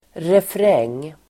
Ladda ner uttalet
refräng substantiv, chorus , refrain Uttal: [refr'eng:] Böjningar: refrängen, refränger Definition: den del av en sång som upprepas Idiom: Nu får vi tänka på refrängen.